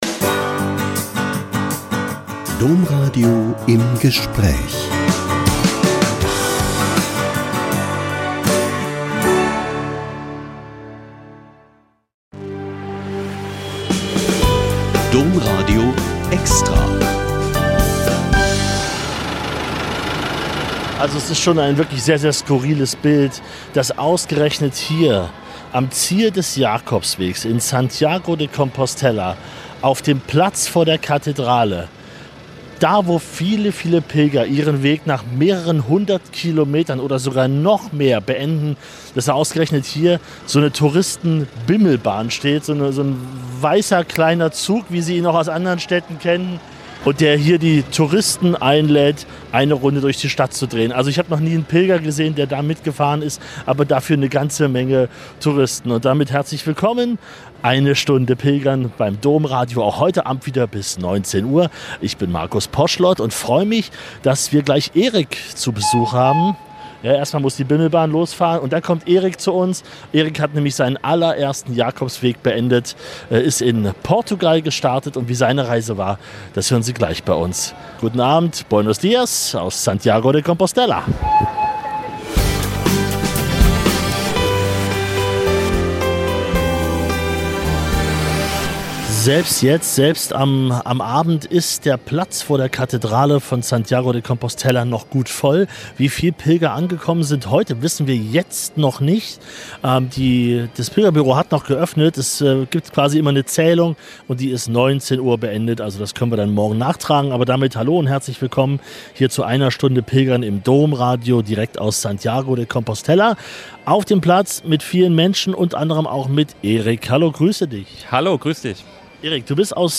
Heute hören wir unter anderem von Pilgerinnen und Pilgern vom Camino del Norte, Camino Francés und Camino Portugues.